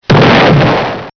collide.mp3